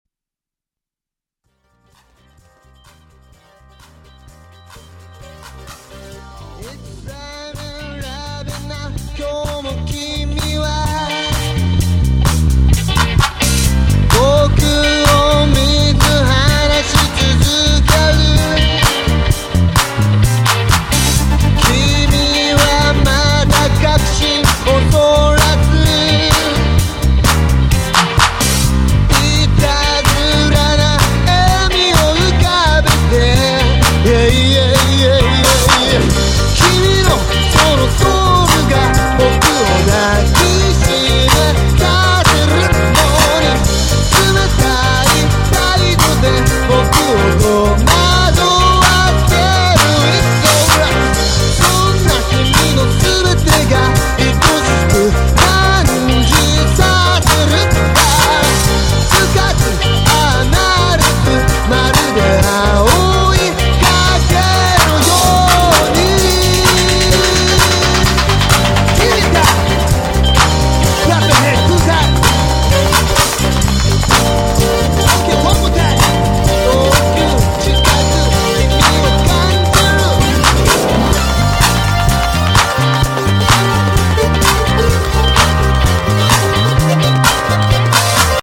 前作とはかなり様相を変え、ハウスやラウンジを強く意識した内容になっている。
全曲にポップな歌がフューチャーされている。